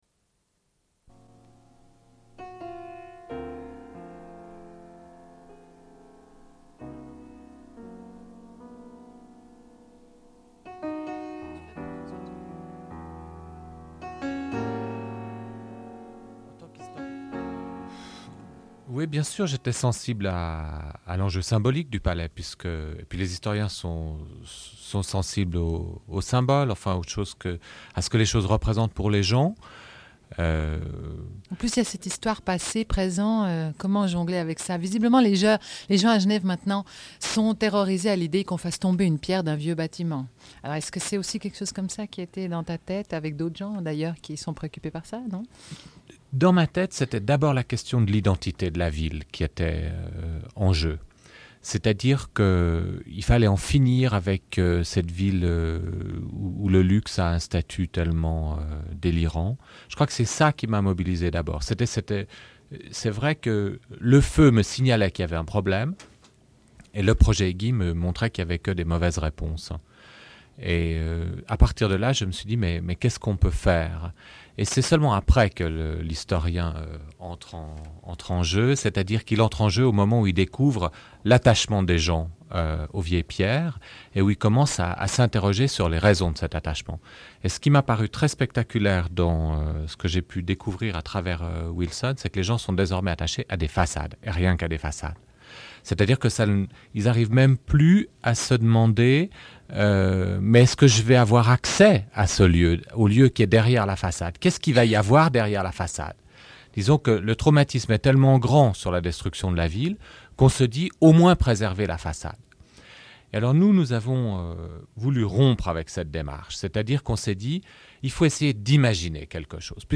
Une cassette audio, face B00:47:30
L'émission se termine avec de la diffusion de musique et communication de quelques événements à venir à Genève.